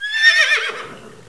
SOUND\HORSE1.WAV